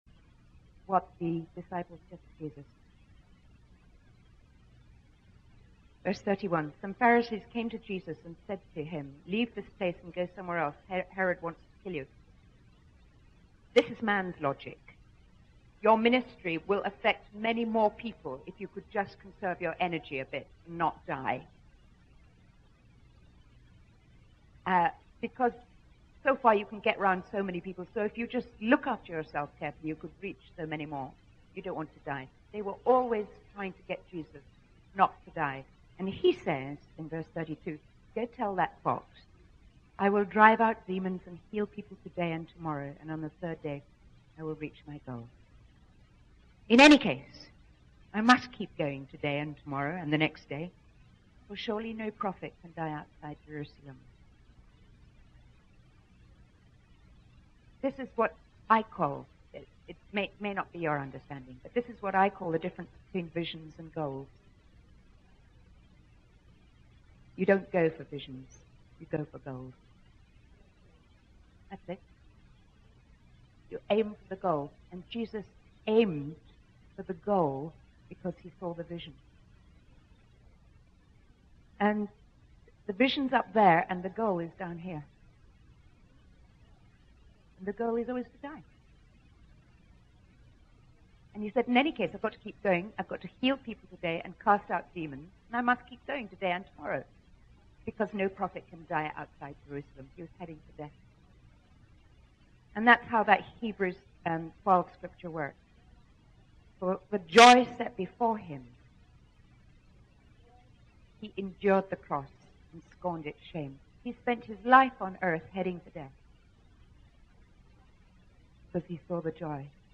In this sermon, the speaker shares a heartbreaking story about a young girl who was sold into prostitution in Nepal due to poverty and gambling debts. The speaker emphasizes the importance of reaching out to the poor and marginalized, as they are the ones who truly understand their need for God. They highlight the need for the church to focus on the 'revealed things' and prioritize ministering to those who are hopeless and unloved.